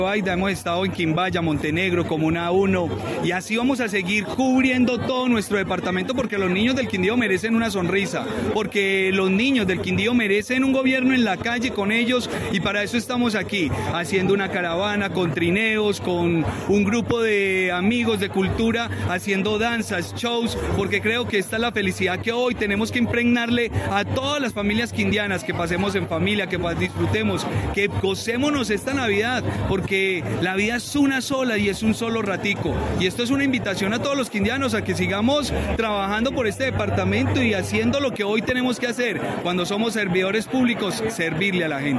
Audio: Juan Miguel Galvis Bedoya, gobernador del Quindío.
Juan-Miguel-Galvis-Bedoya-gobernador-del-Quindio_Recorrido-navideno-por-el-dpto.mp3